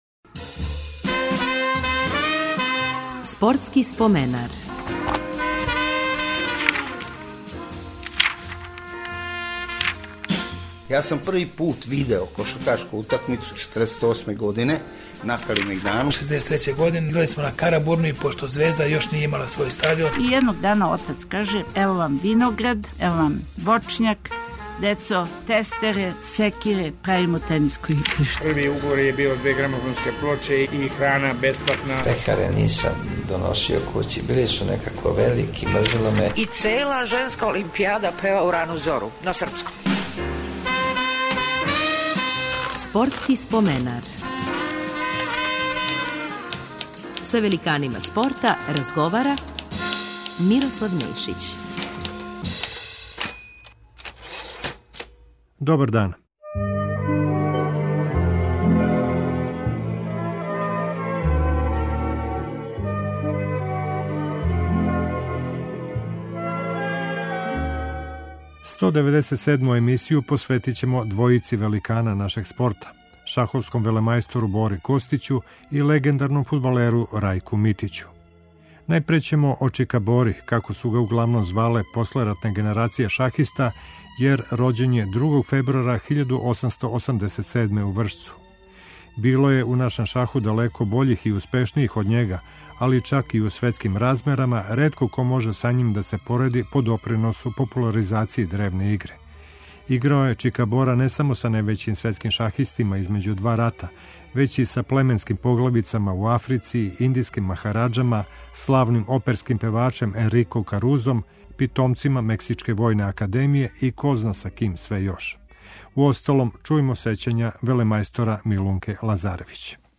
Користећи материјал из Тонског архива Радио Београда подсетићемо вас и на легендарног фудбалера Рајка Митића.